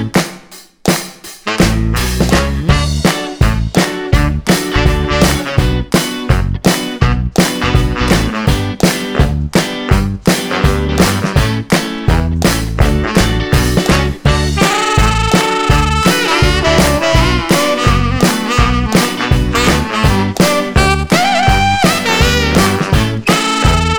No Backing Vocals Rock 'n' Roll 2:42 Buy £1.50